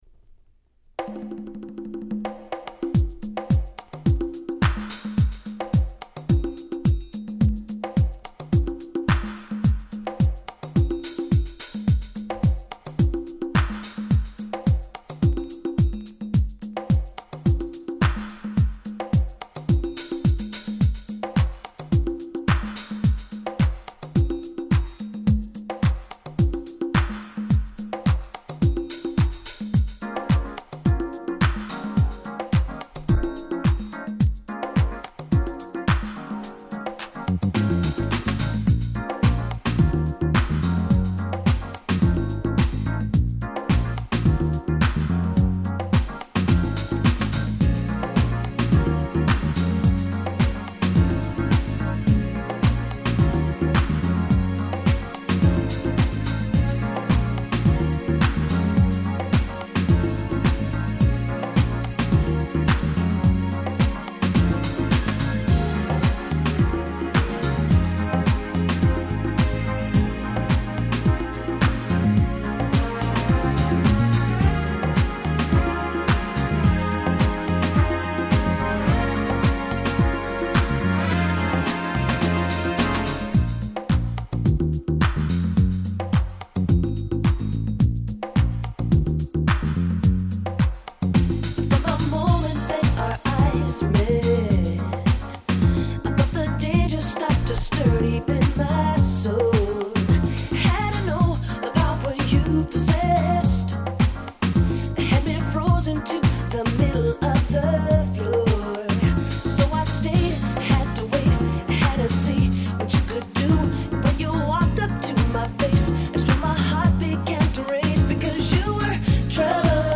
93' Nice UK R&B !!